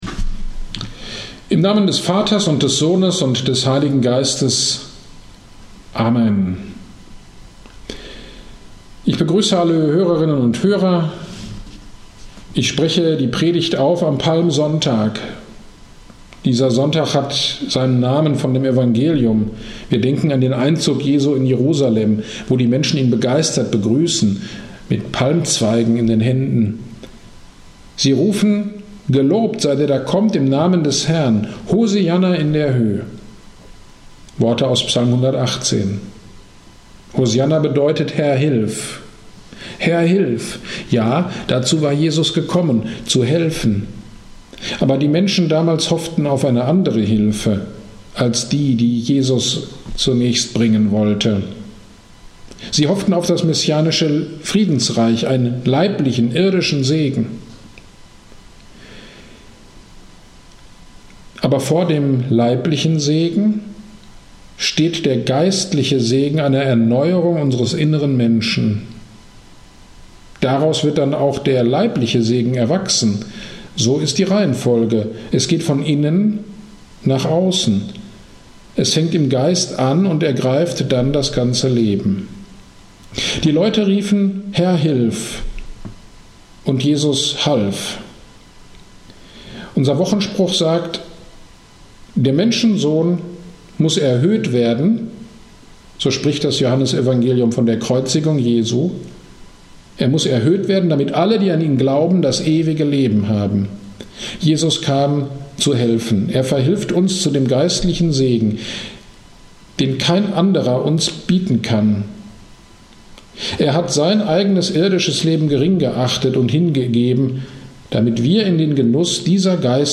GD am 28.03.21 (Palmsonntag) Predigt zu Johannes 19,1-16